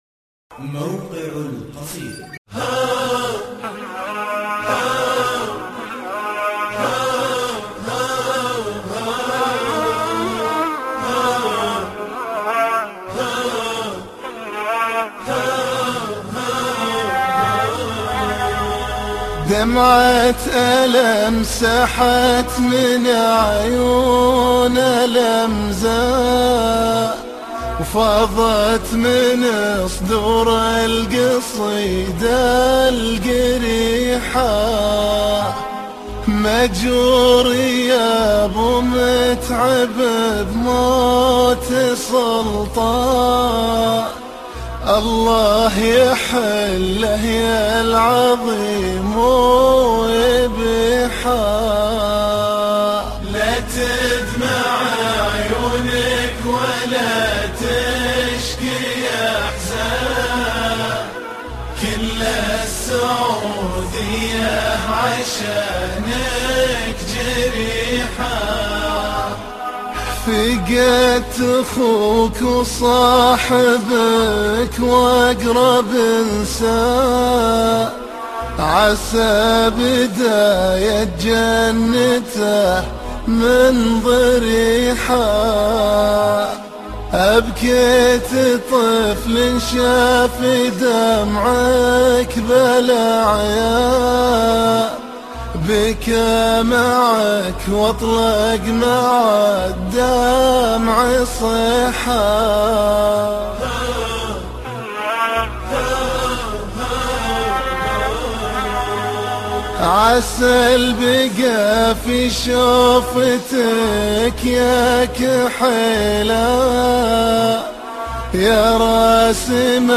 مرثيه